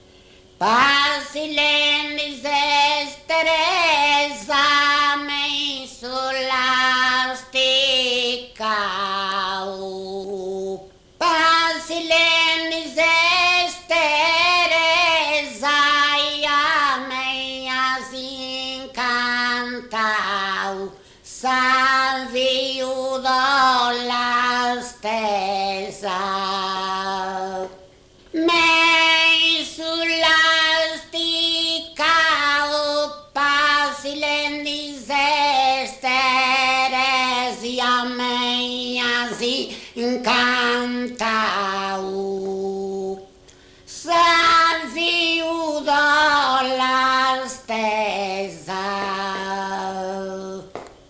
muttetu